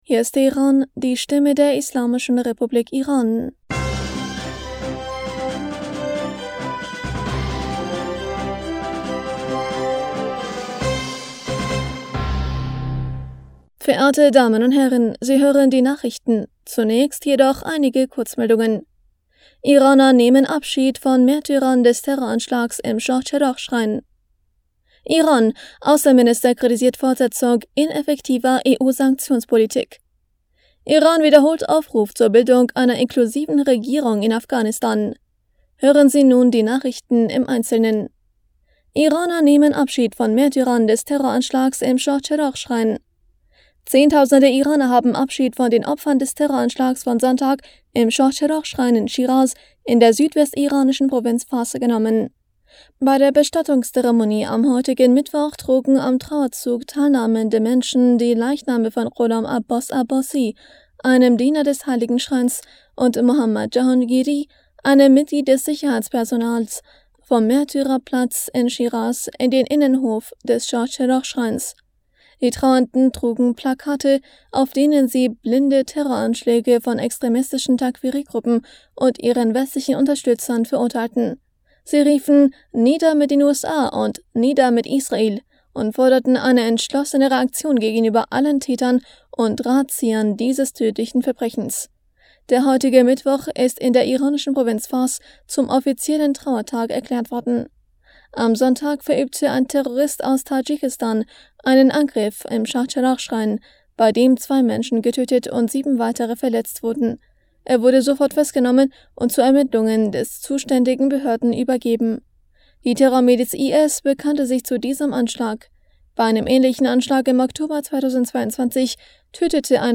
Nachrichten vom 16. August 2023